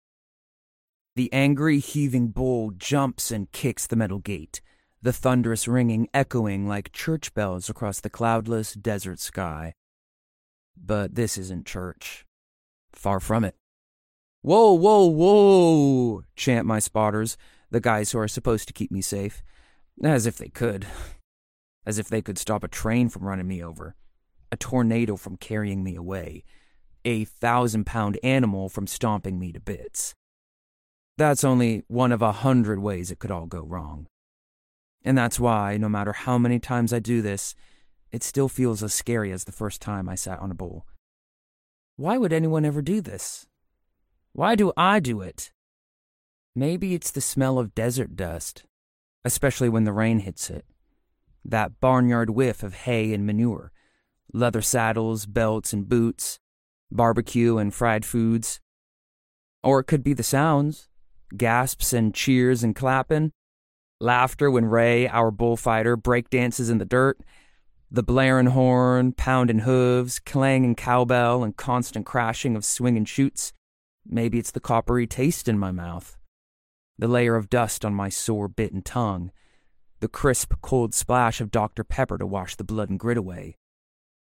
Teens – 30s. US. A lively, expressive and friendly young American voice. Home Studio.
Audiobook